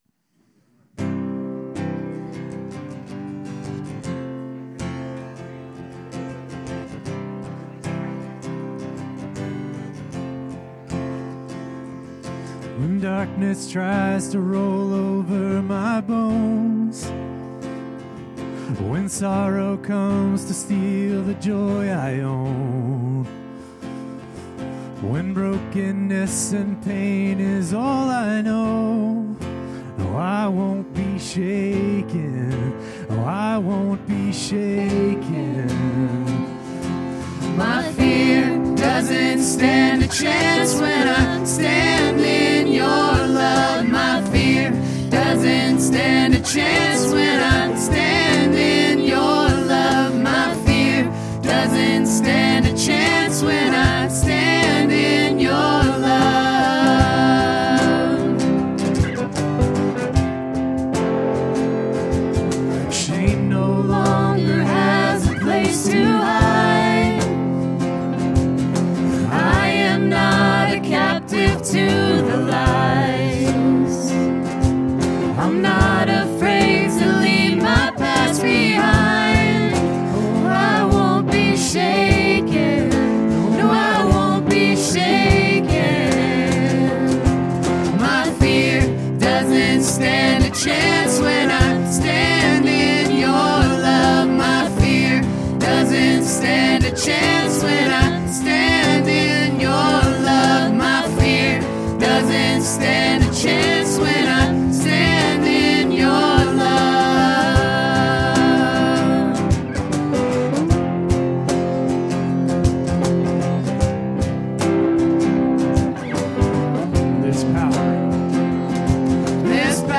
Worship 2025-03-02